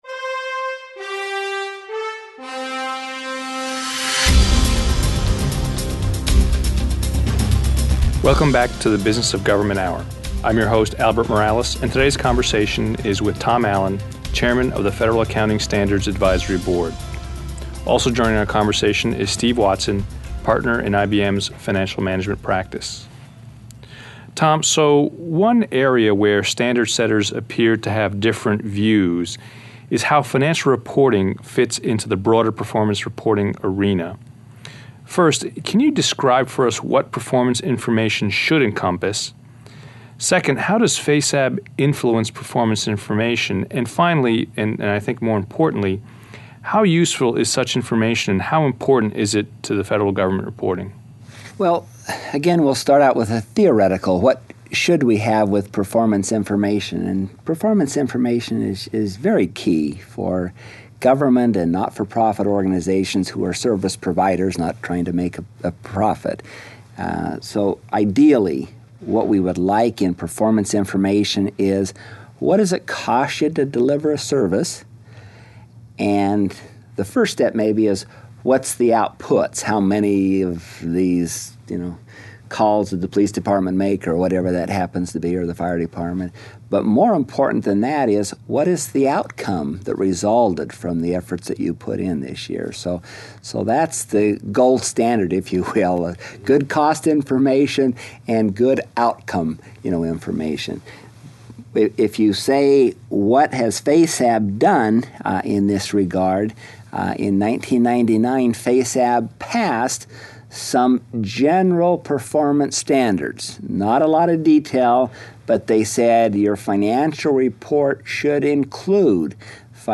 Interviews | IBM Center for The Business of Government